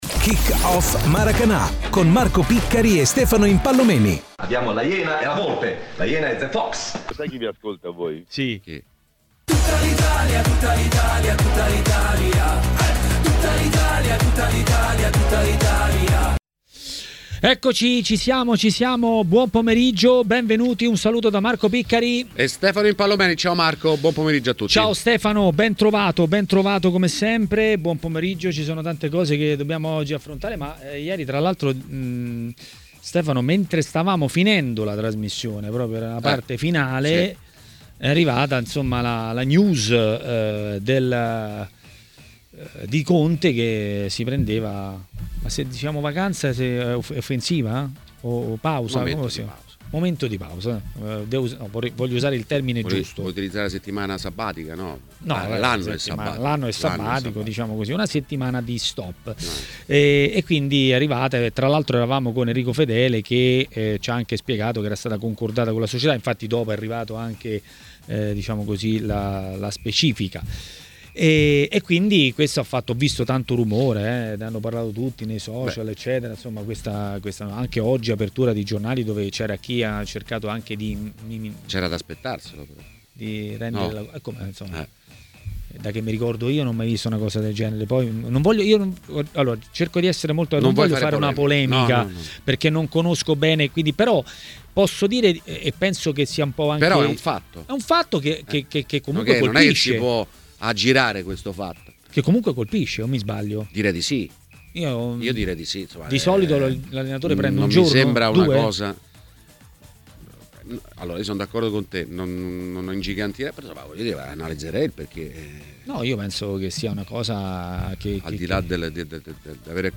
intervenuto sulle frequenze di TMW Radio nel corso di Maracanà